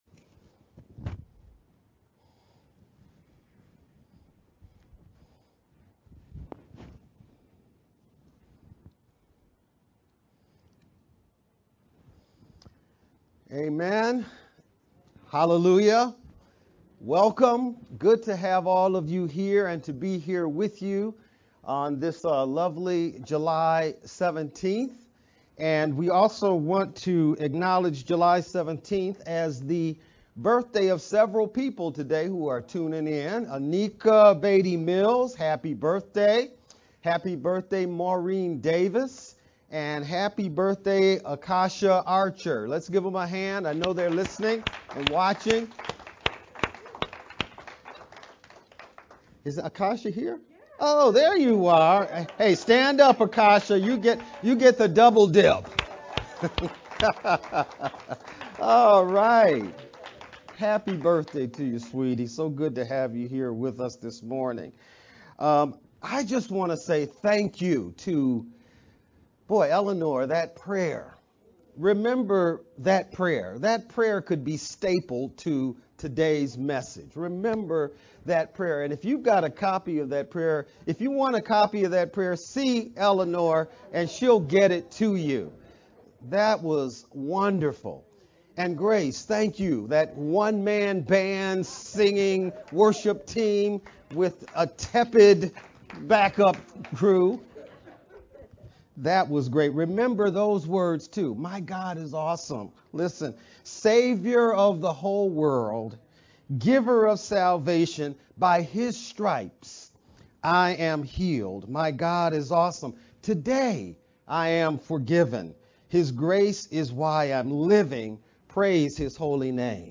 July-17th-Sermon-Mp3-CD.mp3